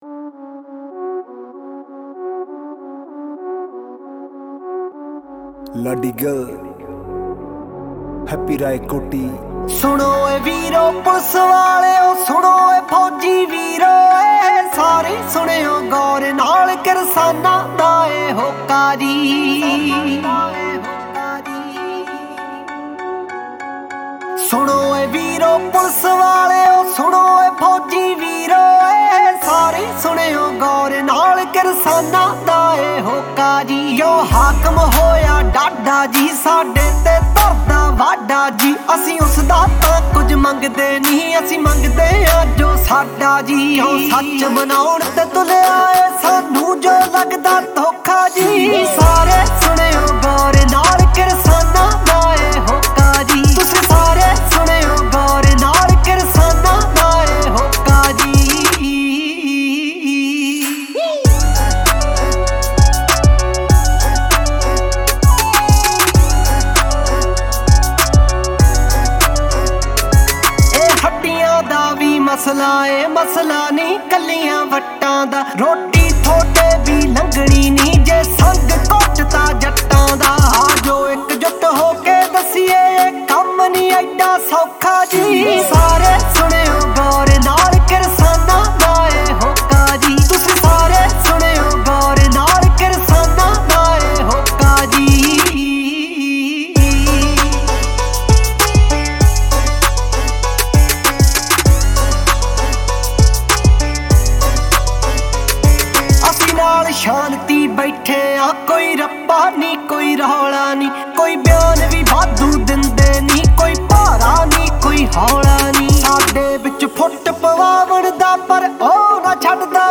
Song Genre : Latest Punjabi Songs